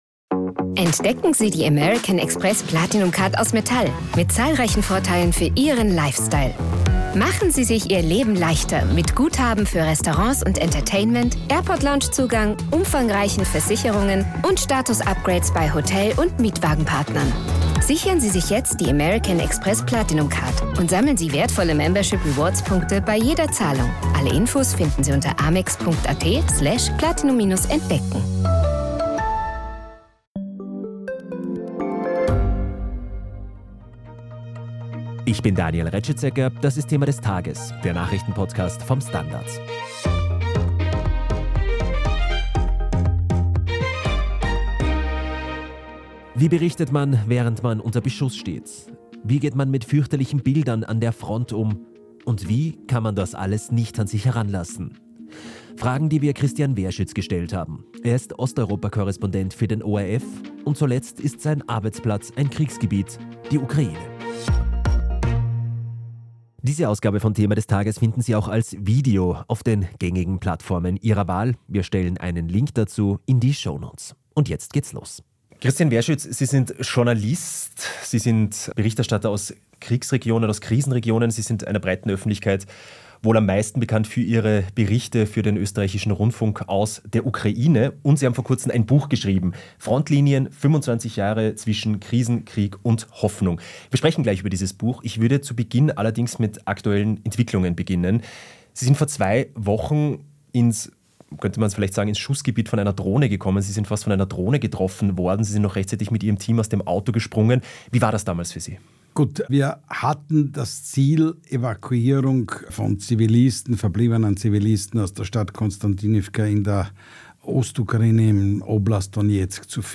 Seit 25 Jahren berichtet Christian Wehrschütz aus Osteuropa für den ORF. Zuletzt intensiv aus einem Kriegsgebiet, der Ukraine. Wir sprechen mit Wehrschütz über seinen Arbeitsplatz an der Front, über sein neues Buch und auch darüber, warum ihm die deutsche Science-Fiction-Reihe Perry Rhodan so gut gefällt.